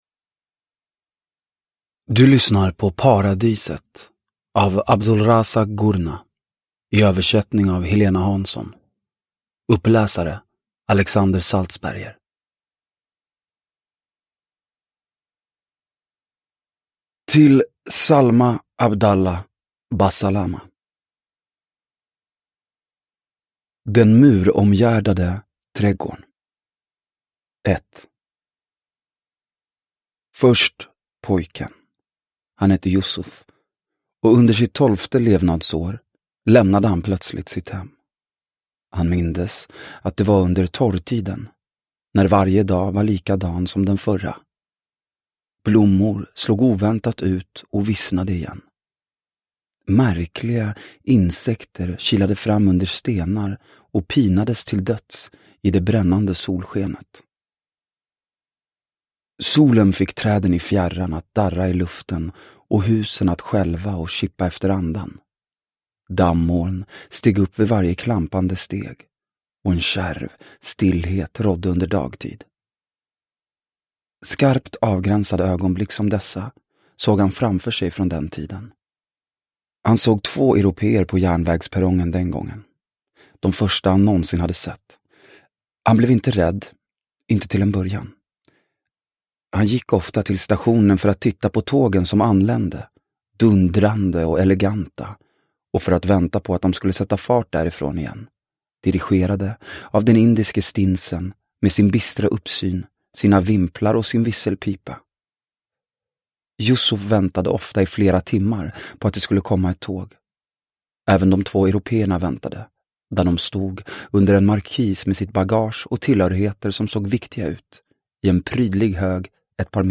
Paradiset – Ljudbok – Laddas ner